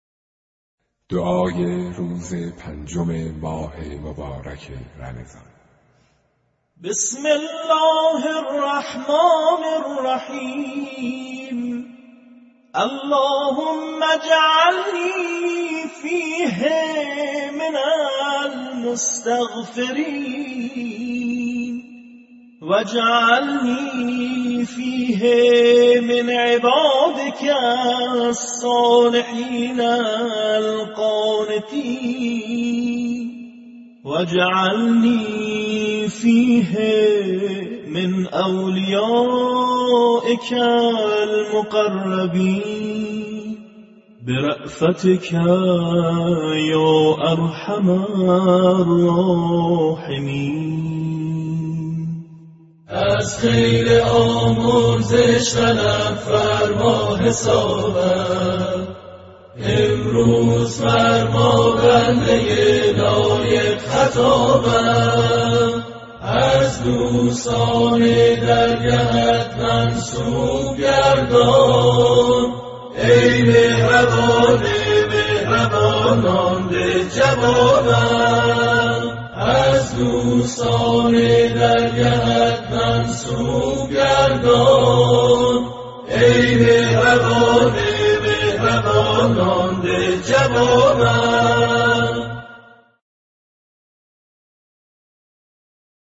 برچسب ها: دعای روز پنجم ، ماه رمضان ، ادعیه ، مناجات ، صوتی